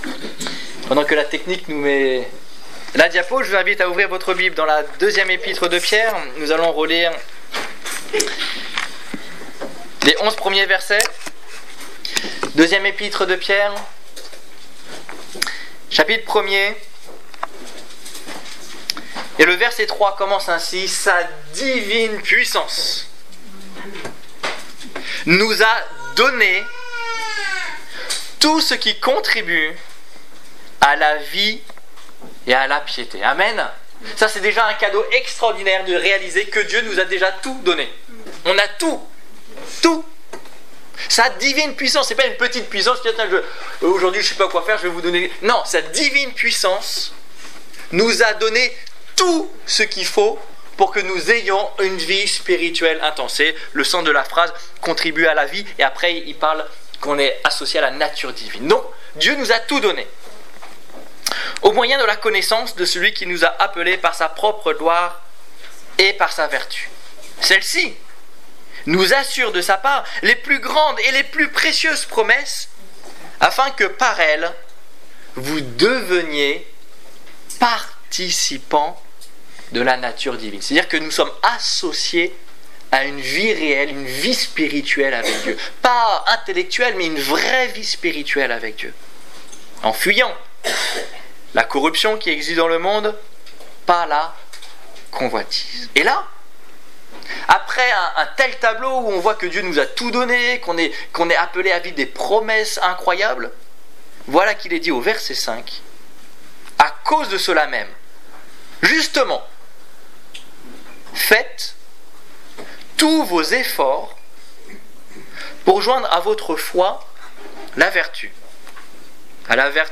Quelques qualités bibliques - La maîtrise de soi Détails Prédications - liste complète Culte du 11 octobre 2015 Ecoutez l'enregistrement de ce message à l'aide du lecteur